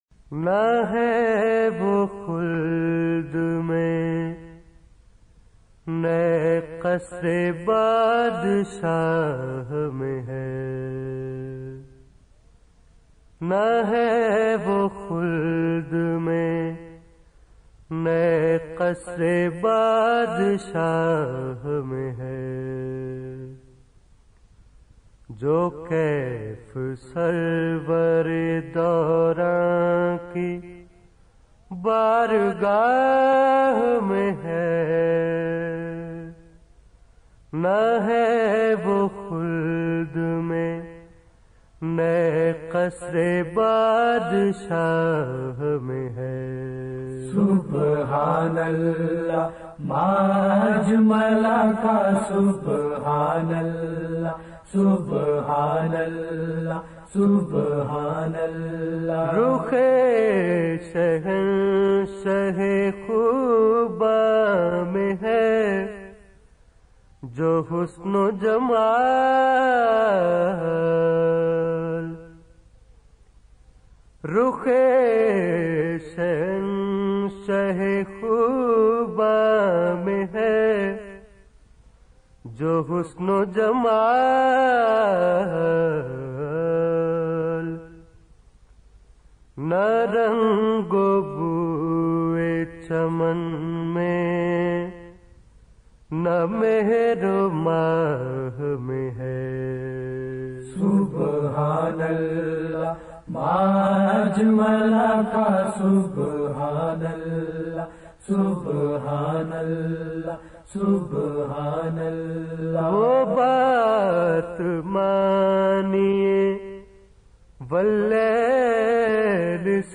in beautifull voice